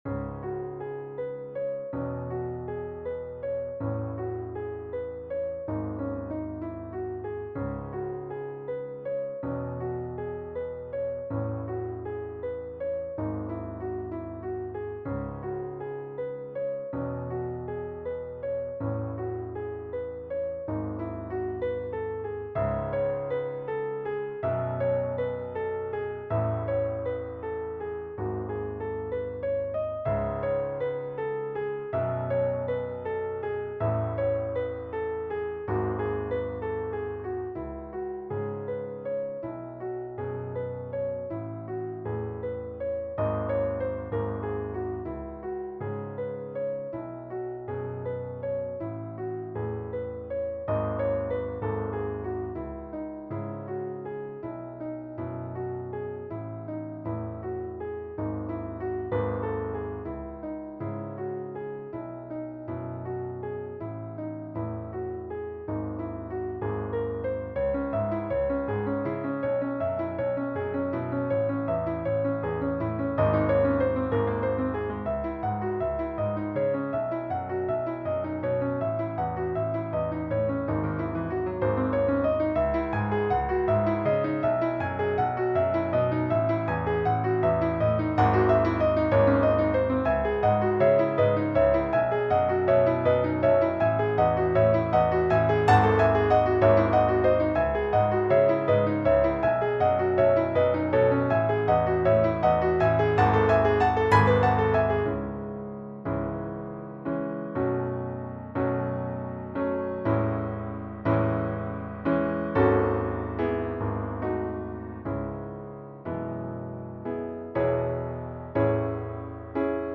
Colorful contemporary chaconne.
Modern Classical